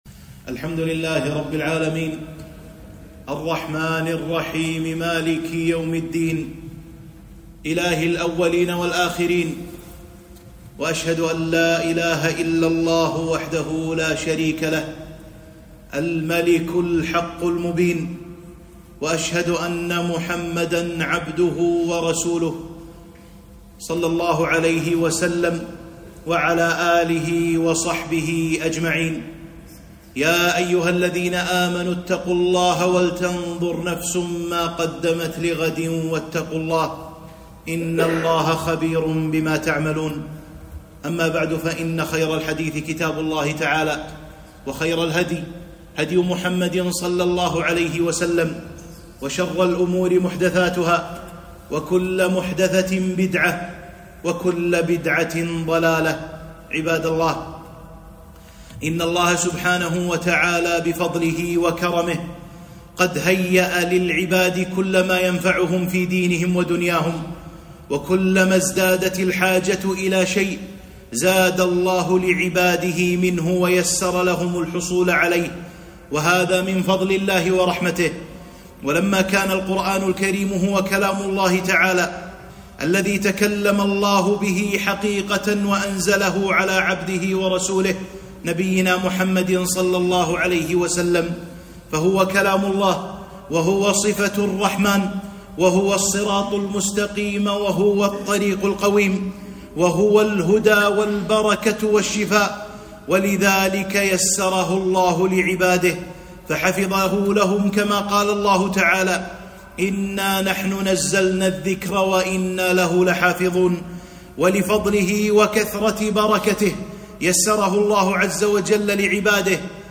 خطبة - سورة الفاتحة، أم القرآن، والسبع المثاني، والقرآن العظيم